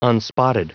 Prononciation du mot unspotted en anglais (fichier audio)
Prononciation du mot : unspotted